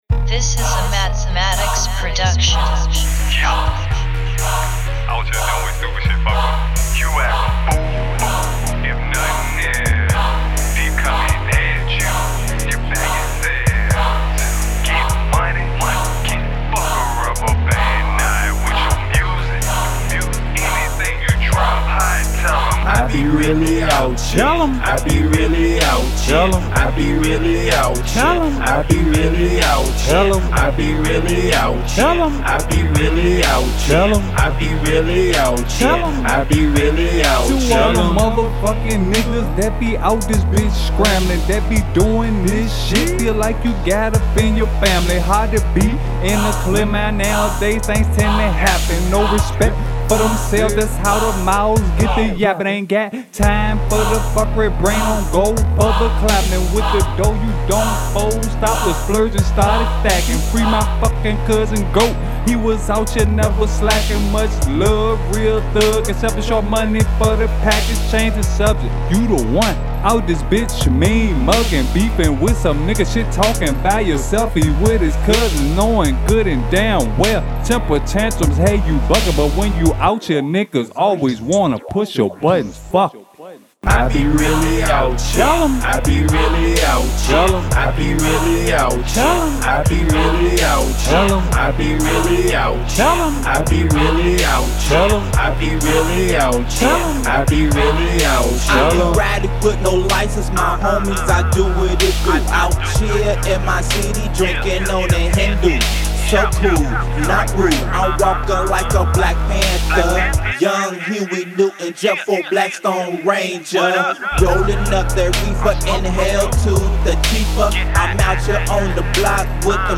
Hiphop
Teamed up on trap classic!